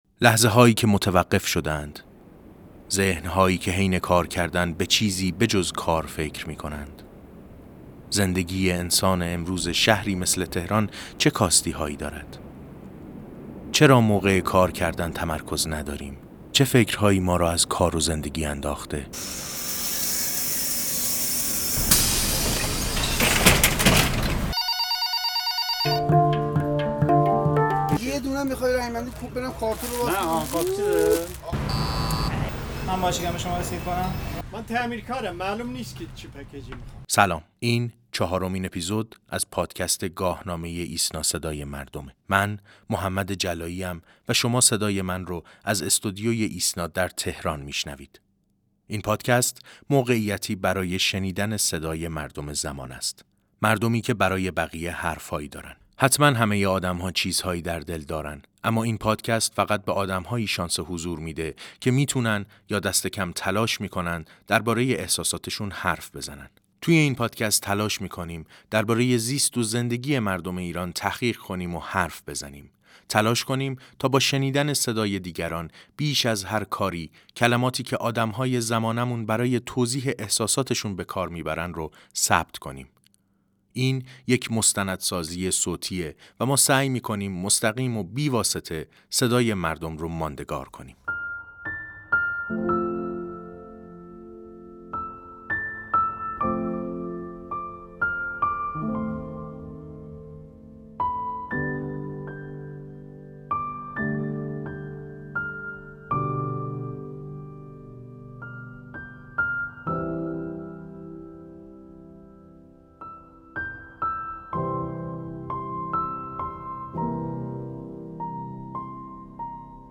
در قسمت چهارم این پادکست ما در جست‌وجوی شمایل چهره آدم‌ها در حین کار به سراغ مردم رفتیم و از آنها پرسیدیم وقتی در حین کار کردن توی فکر و خیال می‌روند به چه چیزهایی فکر می‌کنند؟